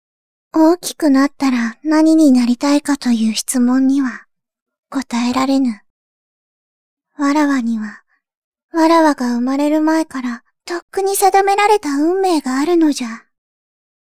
主に少女〜お姉さん声で活動をしています。
ナチュラルな少女声